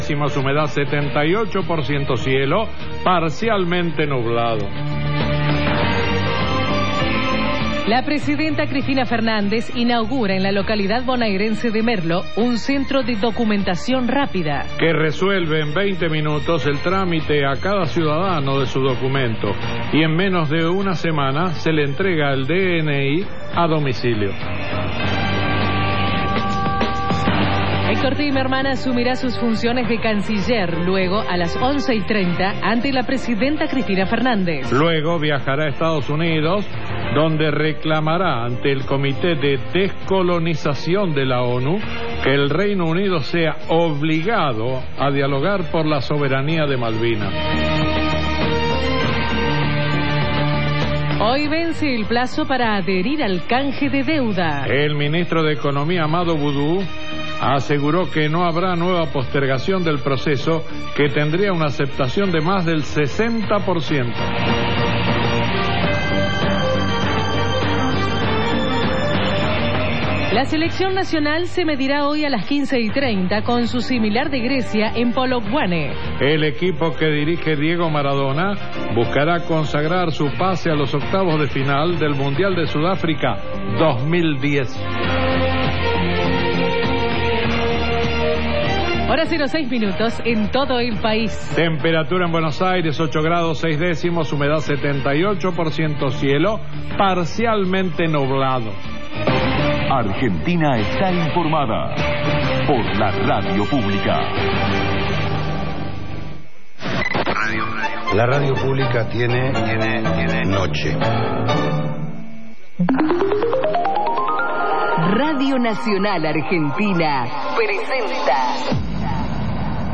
Programa desde el Auditorio de Radio Nacional Dolina